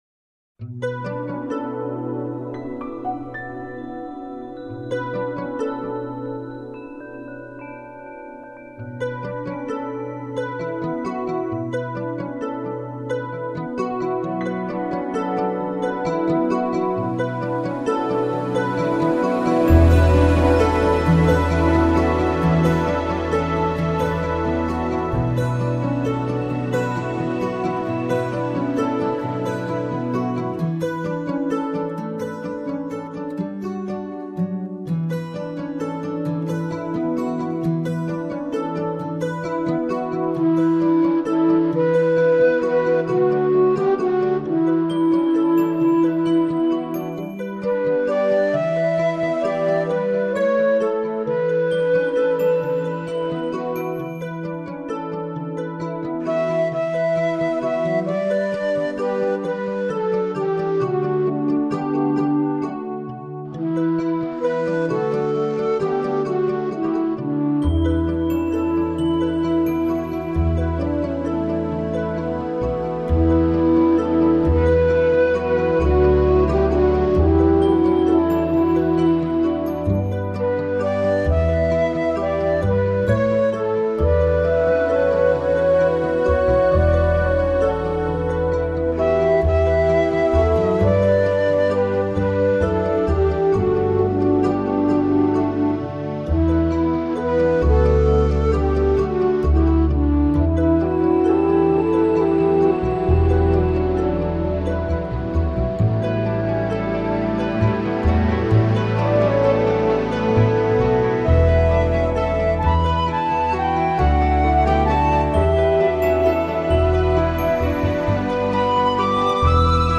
音乐，文字，图片。
这些歌曲都是根据早期的不同音乐元素，高雅和 流行的题材来改编，再由管弦乐团有才气的融合起来的。